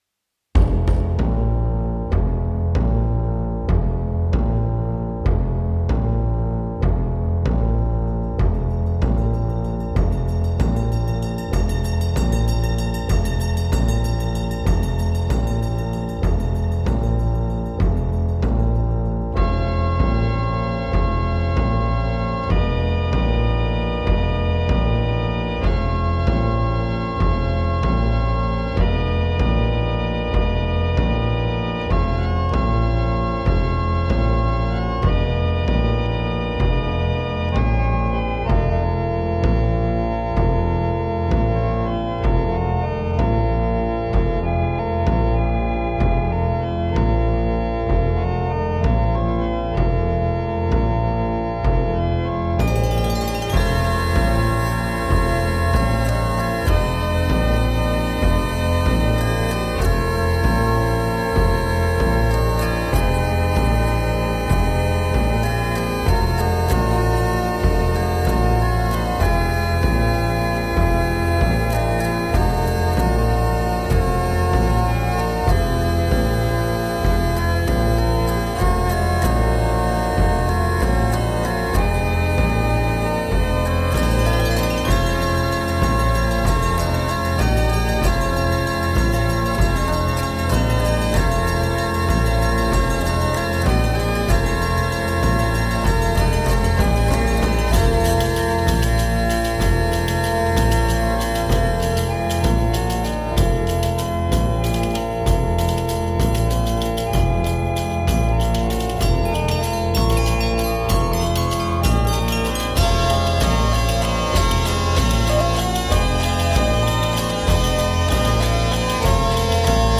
Hurdy gurdy based multitrack music for the end of time.
hurdy gurdy